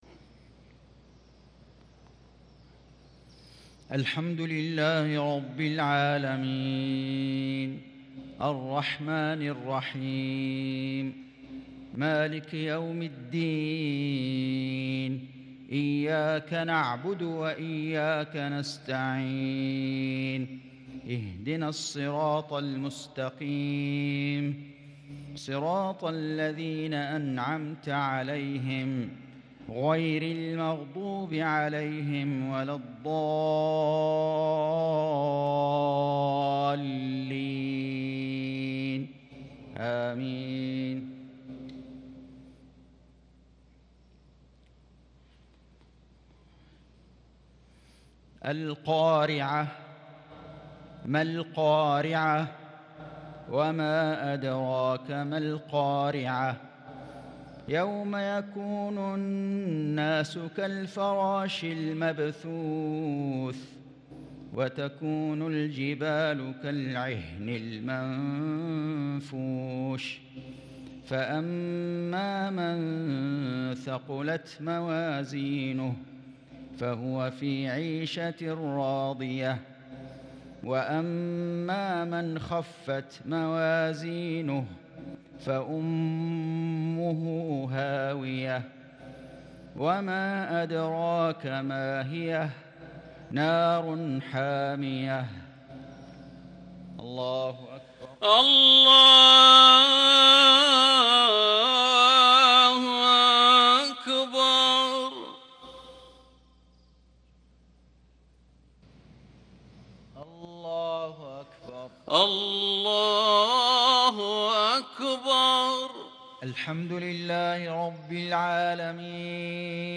مغرب ٤ جمادى الثاني ١٤٤٣هـ | سورتي القارعة والتكاثر | Maghrib prayer from Surah al-Qari`ah & at-Takathur 7-1-2022 > 1443 🕋 > الفروض - تلاوات الحرمين